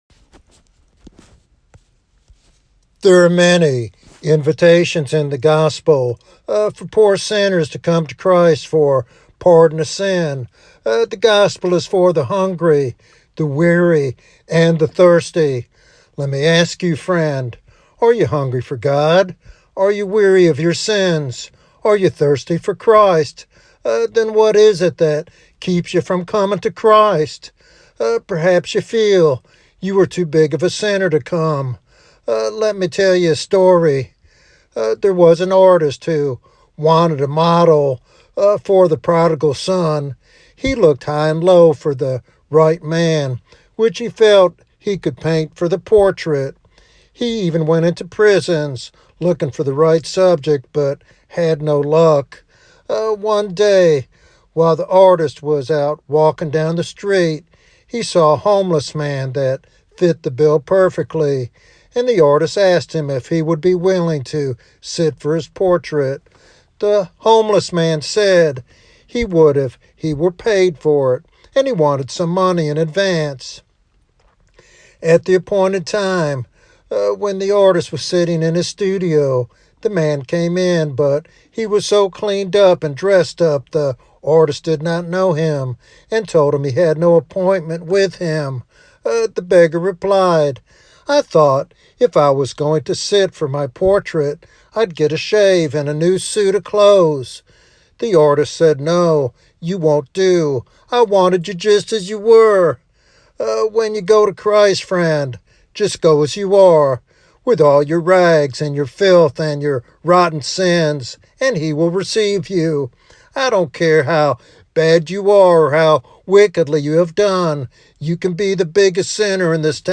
In this heartfelt evangelistic sermon